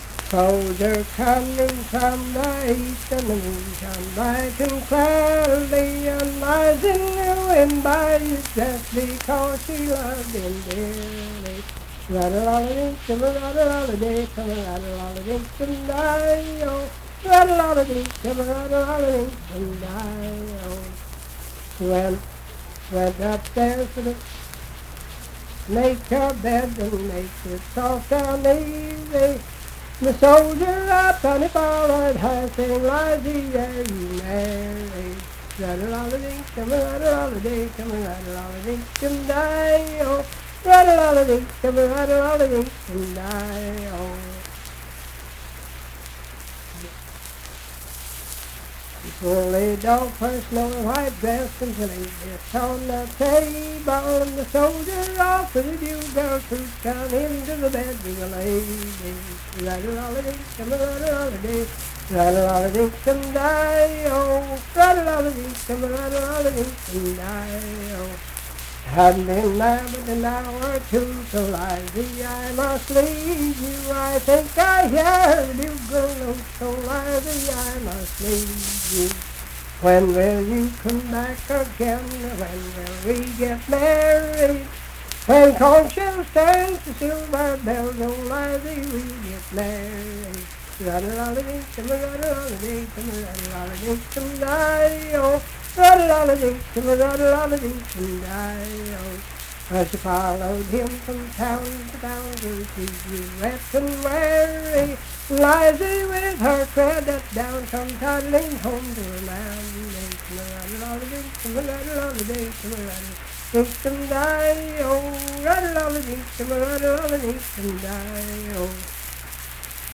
Unaccompanied vocal music
Verse-refrain 6(8w/R).
Performed in Ivydale, Clay County, WV.
Voice (sung)